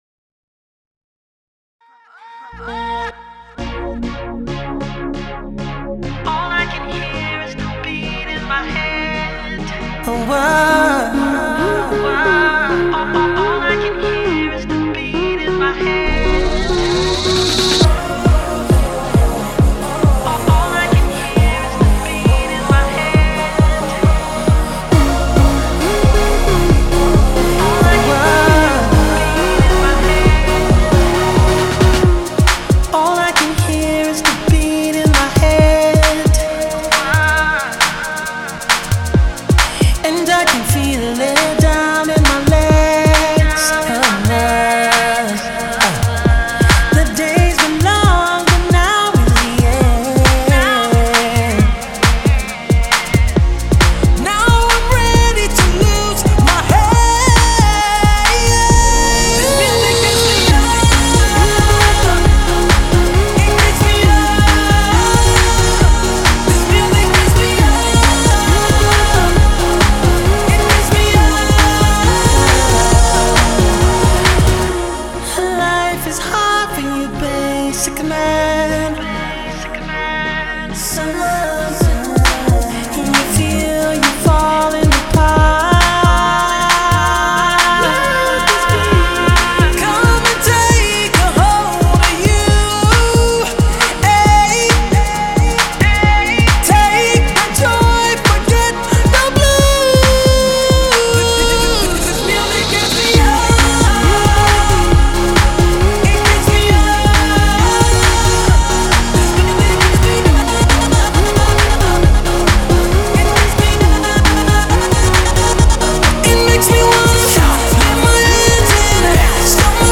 the party/club track